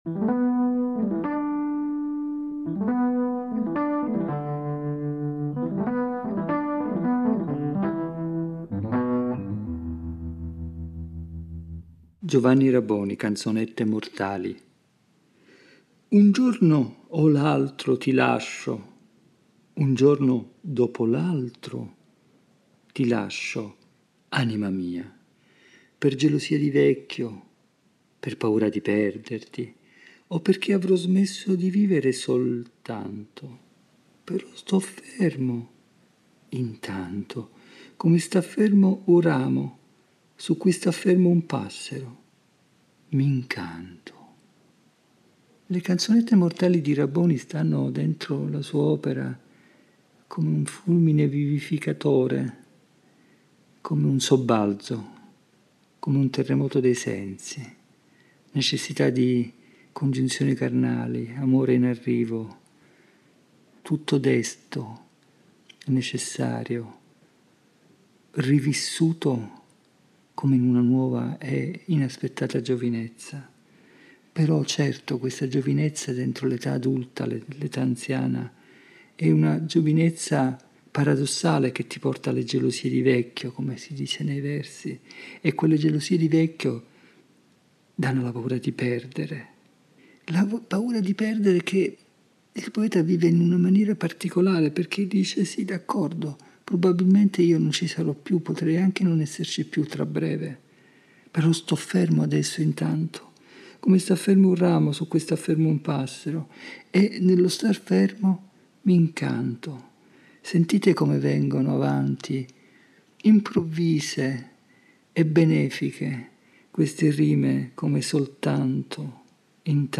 Ed è a partire da questo simbolo "delle equazioni casalinghe" che hanno caratterizzato il tempo del lockdown (ogni registrazione è stata pensata e realizzata fra le mura domestiche) che egli ci guida nella rigogliosa selva della parola poetica per "dare aria ai pensieri".